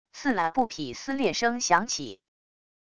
刺啦――布匹撕裂声响起wav音频